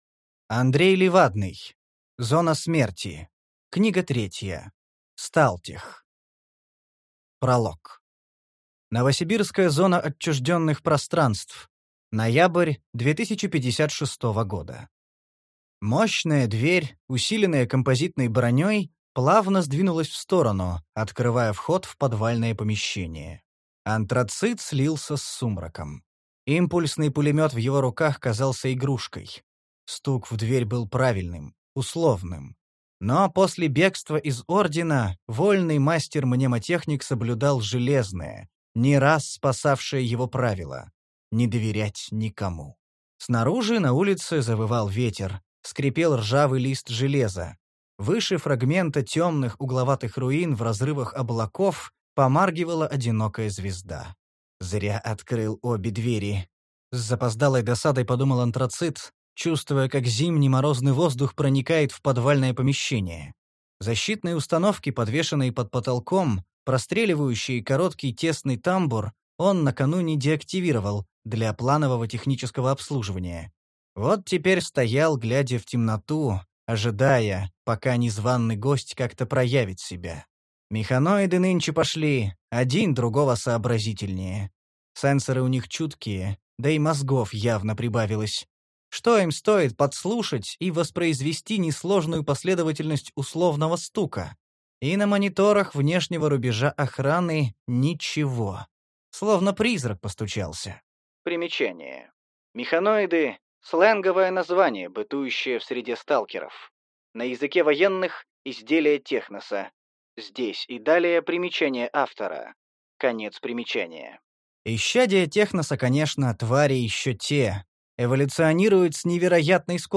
Аудиокнига Сталтех | Библиотека аудиокниг